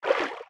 Sfx_creature_spinner_swim_start_01.ogg